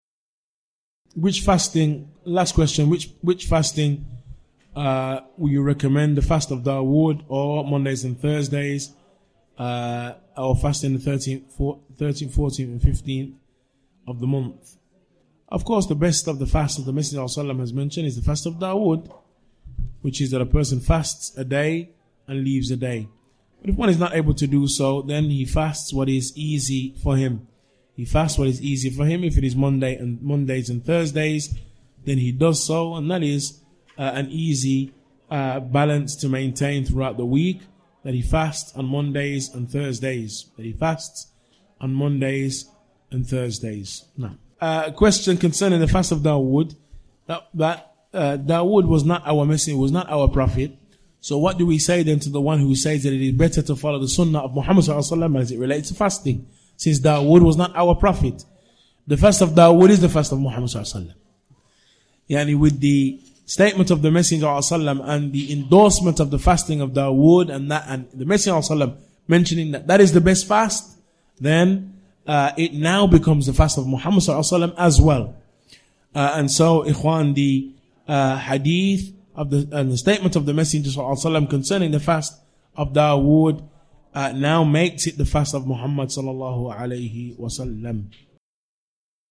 This is a question that was extracted from the Dawrah ‘ilmiyyah – The Greatness of Allah Necessitates we Worship Him Alone, Q&A session 2.